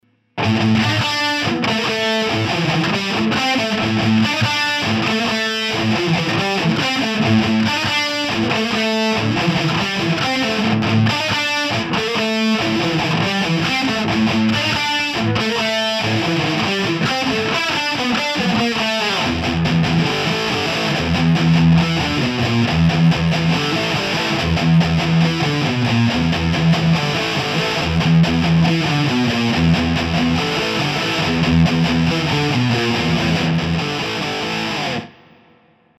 LINE6 TONEPORT UX2でサンプルサウンドを作ってみました。
JCM800を選択してLがマイキング50％、Rがマイキング100％、オフマイクです。
よい音鳴っております♪ちなみに使用ギターはEdwards E-FR-120GTです。
80年代を狙ってみた。。。
JCM800のモデリング